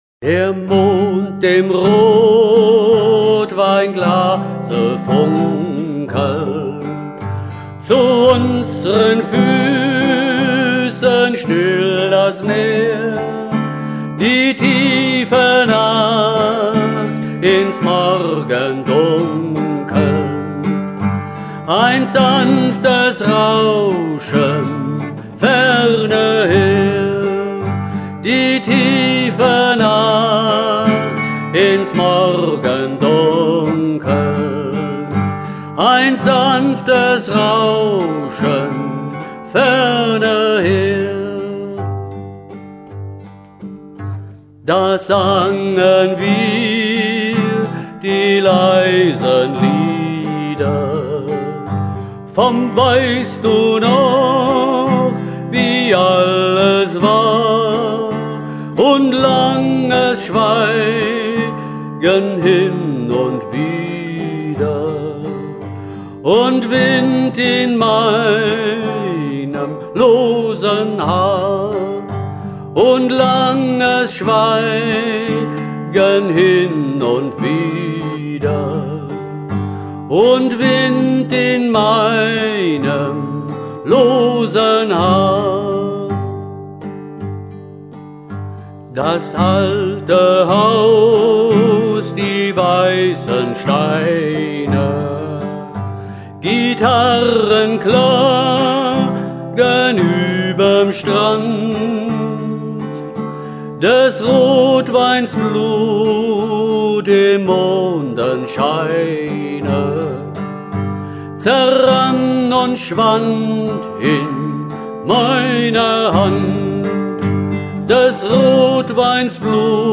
Vertonung eines Verena C. Harksen Gedichtes
Es sollte ein Lied für die ruhigen und besinnlichen Momente eines Abends werden.
Ich habe ihr nun etwas mehr Schwung verliehen und durch den zeitlichen Abstand, verfiel ich auch nicht in die alten Fahrwasser der vorherigen Weise.